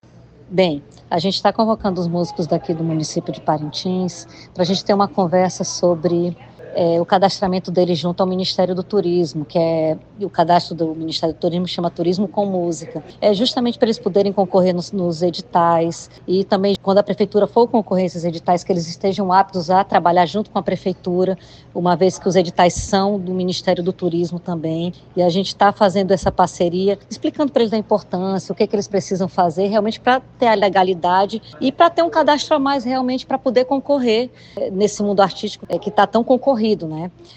A secretária de turismo e eventos, Karla Viana, explica que esse momento é essencial para que música cultural parintinense esteja no mapa nacional.